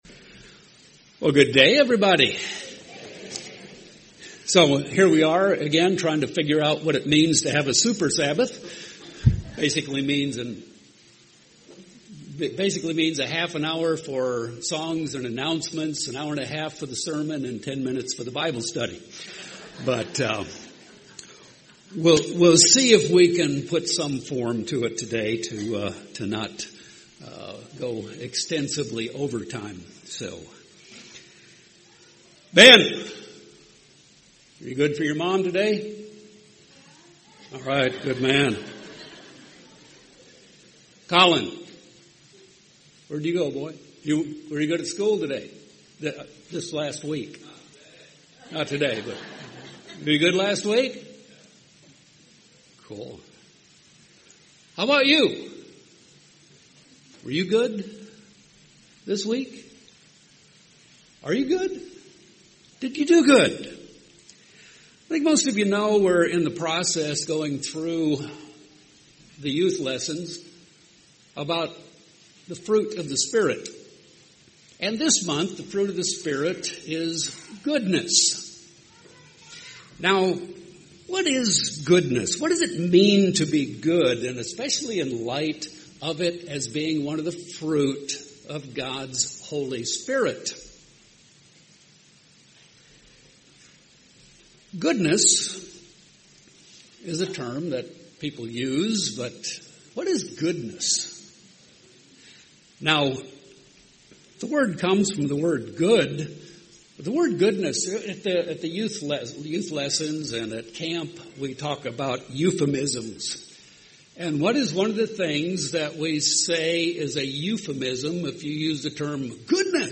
This sermon will pursue these important questions.
Given in Phoenix Northwest, AZ